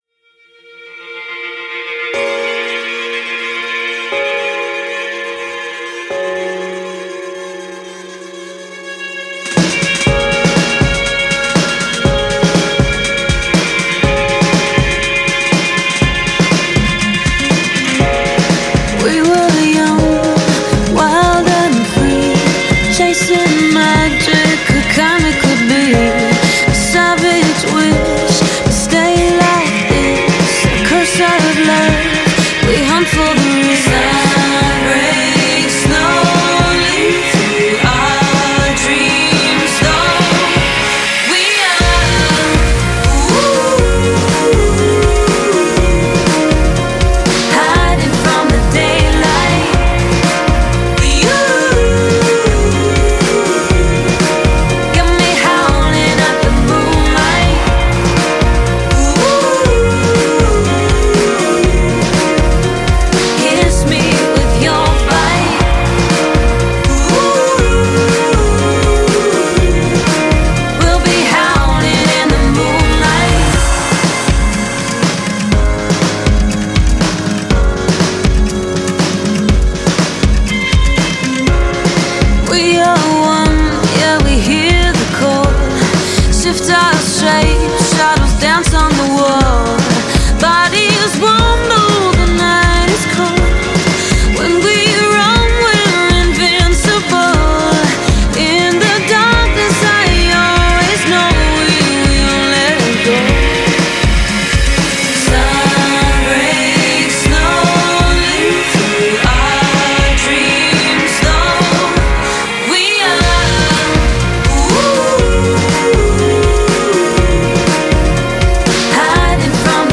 this is a very well recorded track. i focus on energy.
some eq is going on, compression, delays and reverbs.